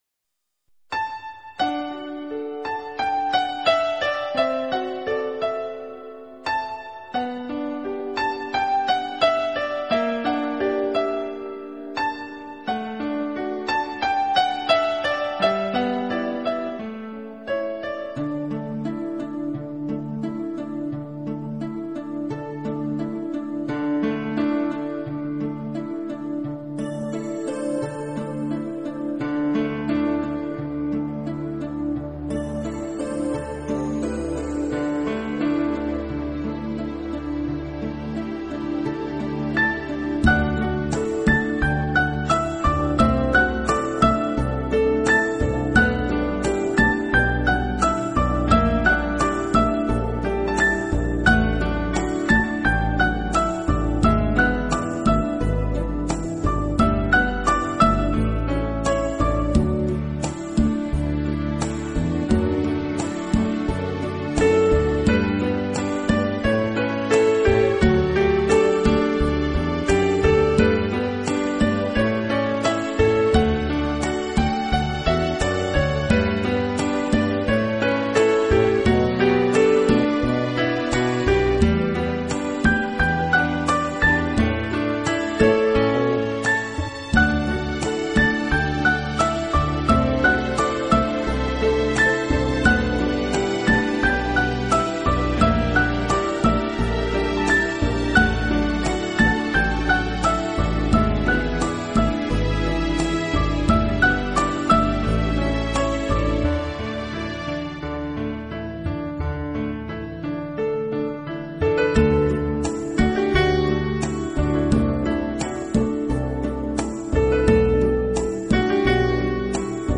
音乐流派： New Age/CLassical